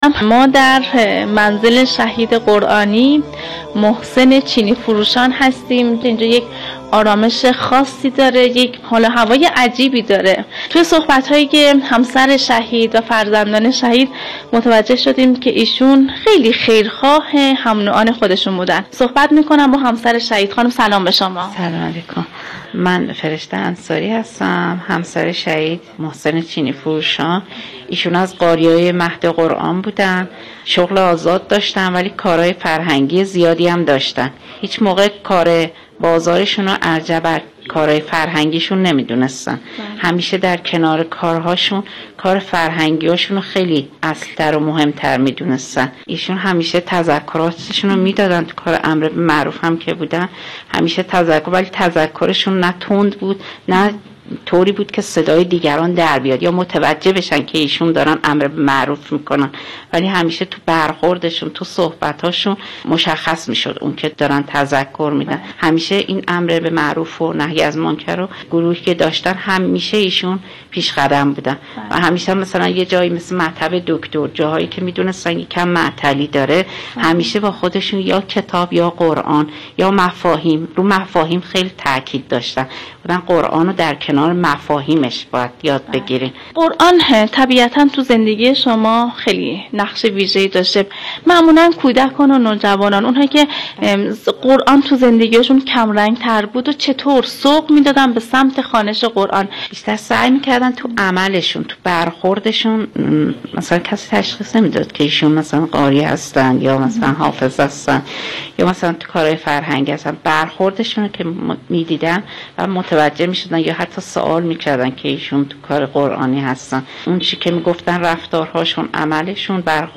به گزارش ایکنا، حکایت جاوادنگی، عنوان برنامه تولیدی شبکه رادیویی قرآن است که عصرهای پنج‌شنبه با محوریت بزرگداشت مقام شهدا به روی آنتن می‌رود.